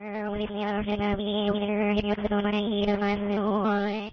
beewater.ogg